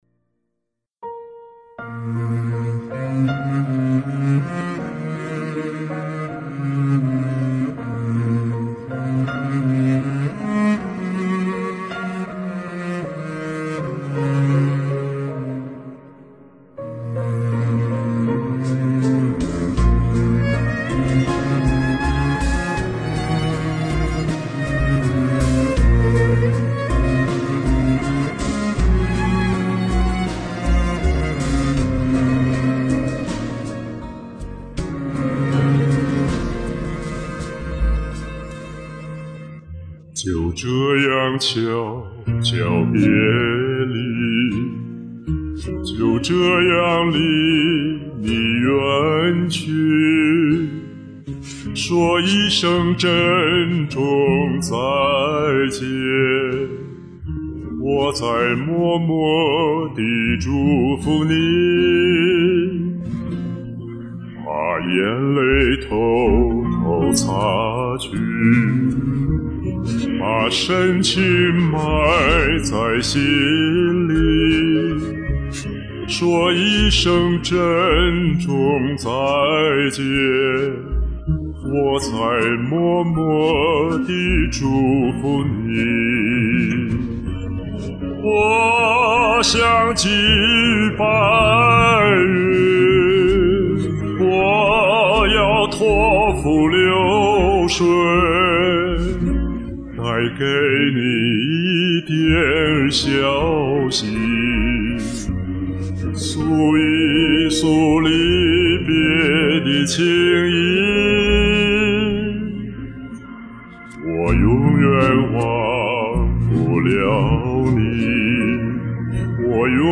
这磁实的男中音确实让人想起故人。
深情的歌声...
音色优美浑厚，歌声流畅自然，表达深情感人！大赞！